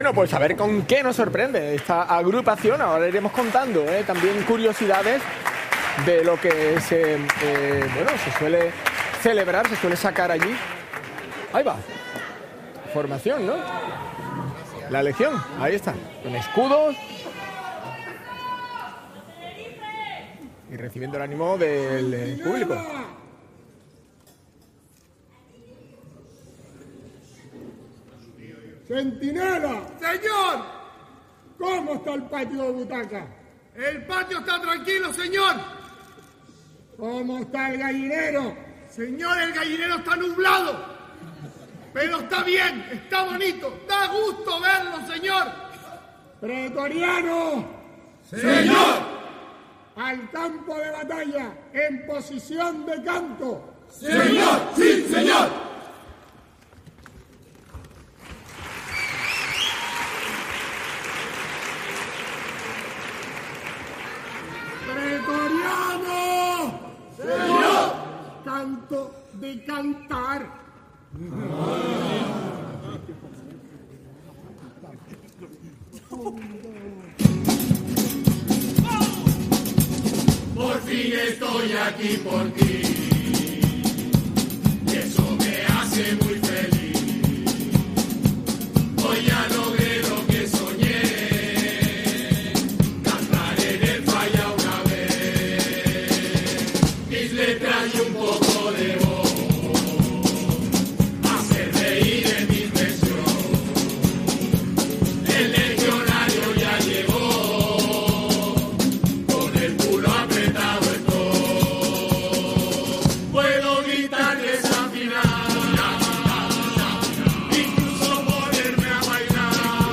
Chirigota Los legías con G - Preliminares - COAC MP3
Disfruta de la actuación brindada por la Chirigota Los legías con G en la fase preliminares del COAC Carnaval de Cádiz 2026.
Chirigota,_Los_legías_con_G_-_Preliminares.mp3